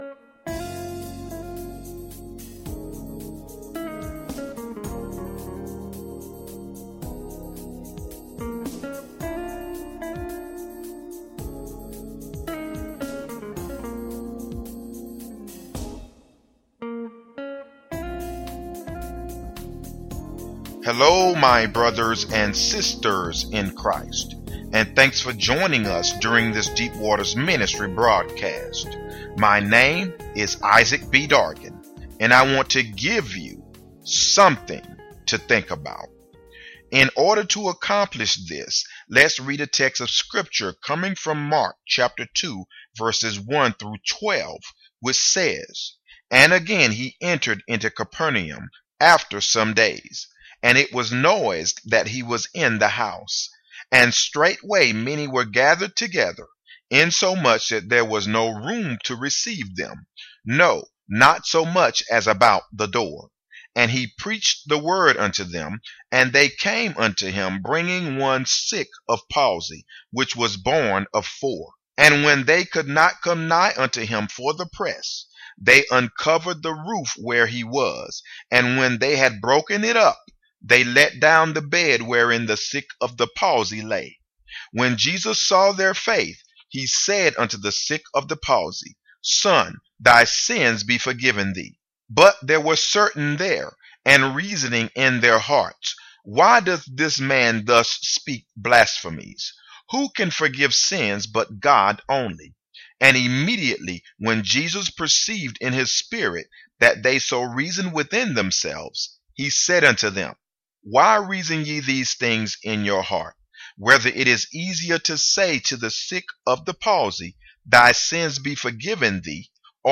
Listen to this Eye-opening Sample Radio Broadcast Teaching! -